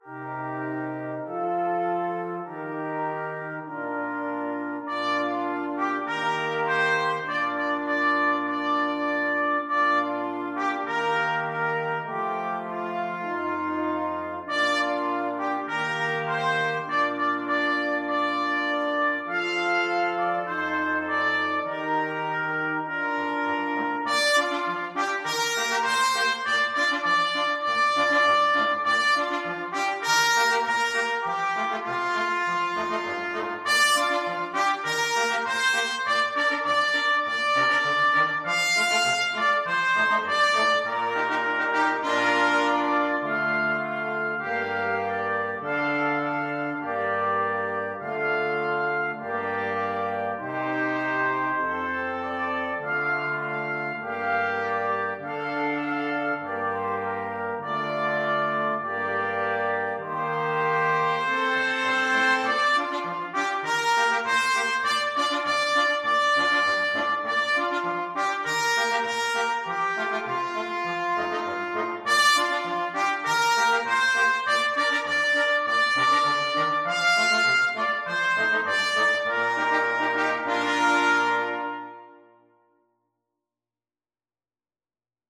4/4 (View more 4/4 Music)
Moderato =c.100